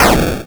ihob/Assets/Extensions/explosionsoundslite/sounds/bakuhatu39.wav at master
bakuhatu39.wav